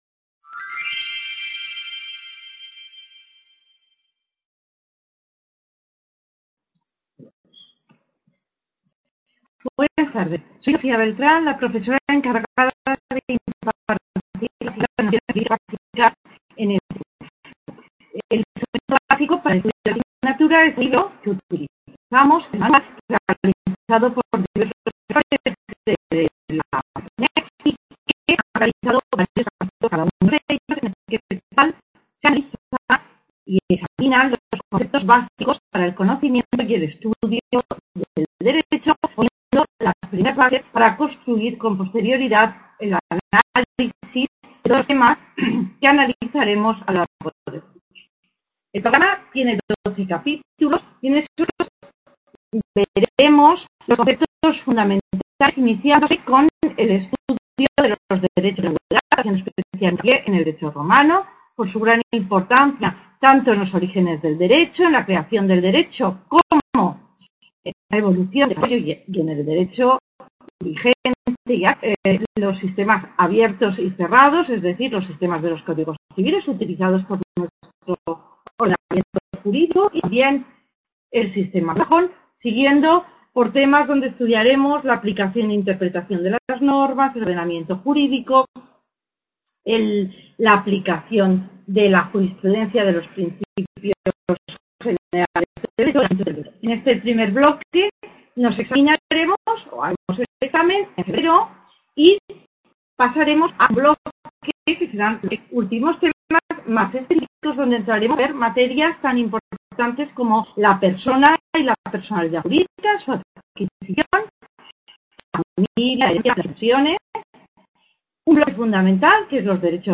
Clase introductoria